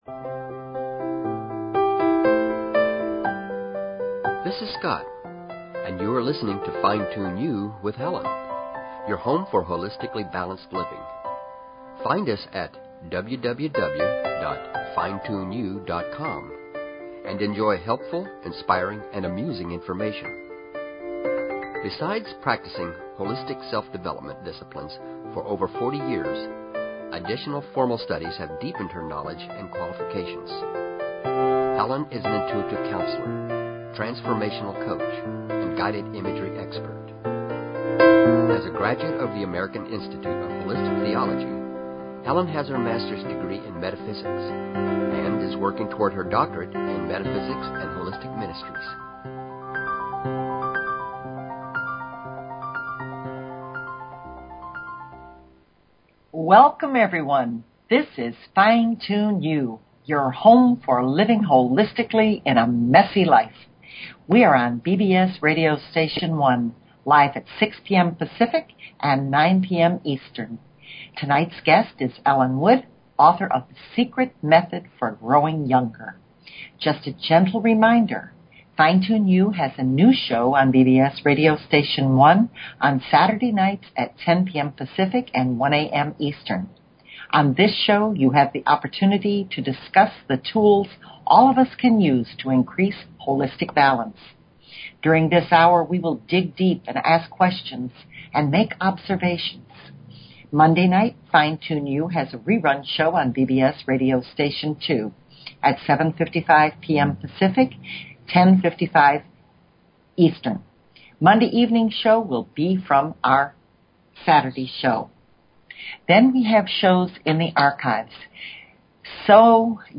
Talk Show Episode, Audio Podcast, Fine_Tune_You and Courtesy of BBS Radio on , show guests , about , categorized as